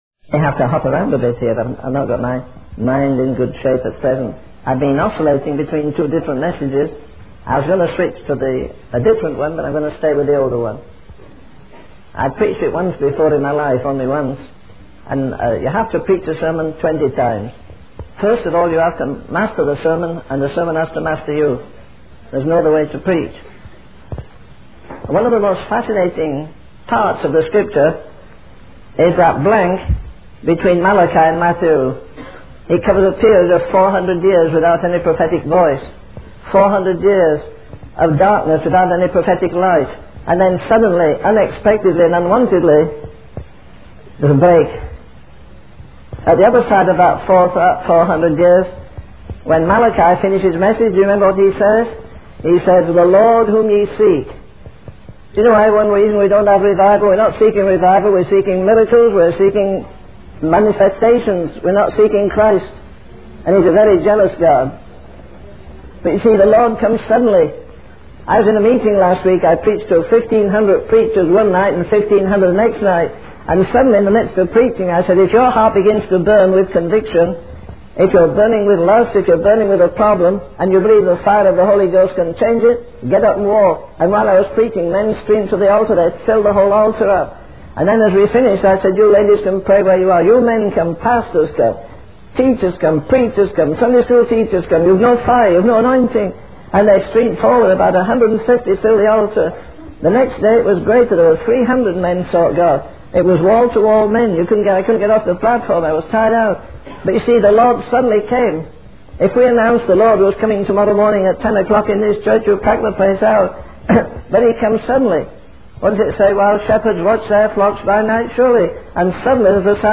In this sermon, the preacher emphasizes the need for a divine intervention in the world.